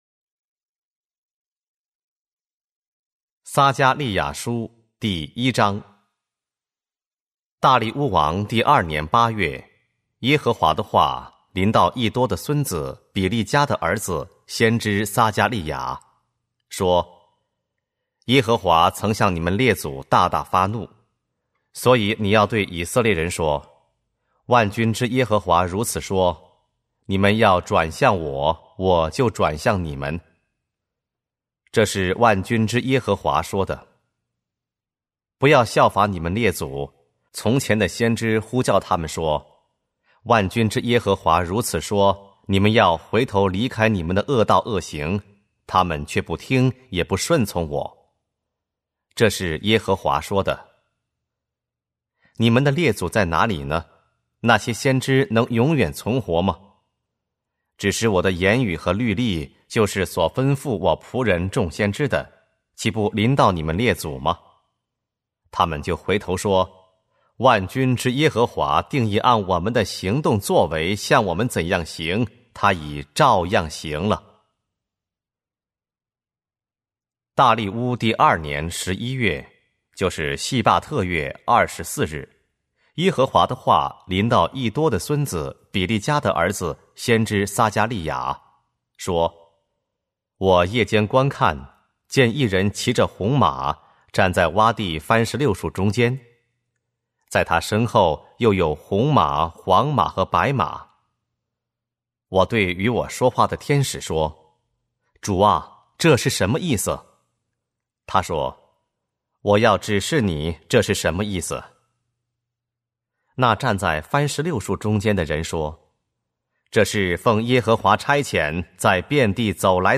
和合本朗读：撒迦利亚书